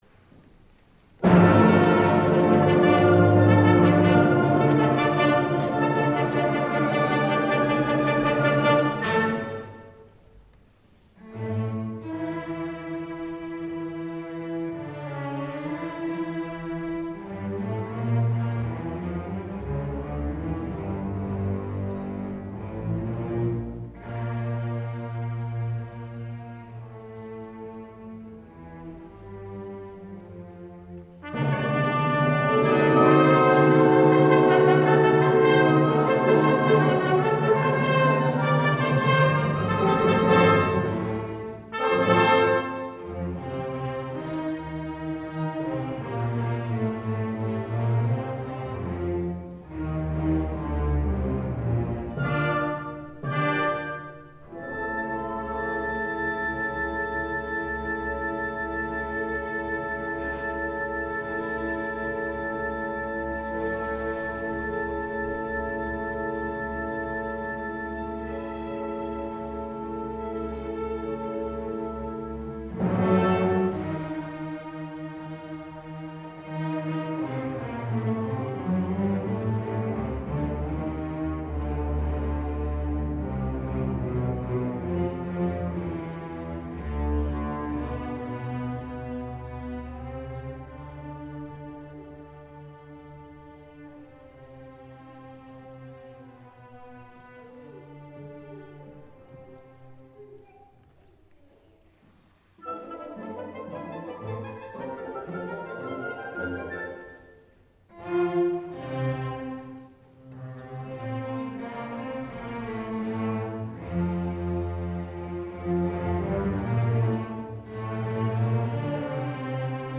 Santa Barbara City College Symphony and Chorus Concert, May 10, 2003
SBCC Symphony and Concert Choir Concert Recording - May 10, 2003